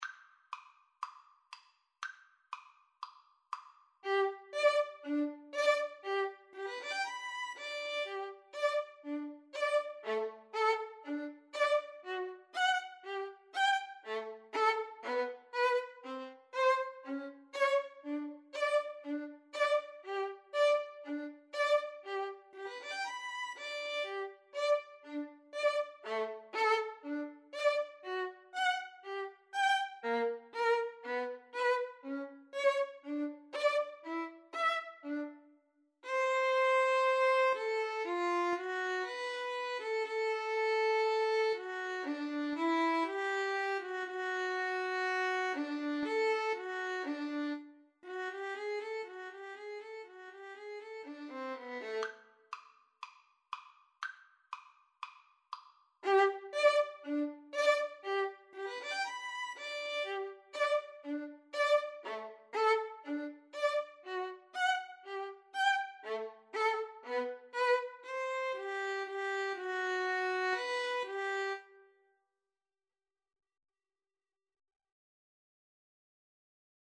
Violin 1Violin 2
4/4 (View more 4/4 Music)
Allegro (View more music marked Allegro)
Classical (View more Classical Violin Duet Music)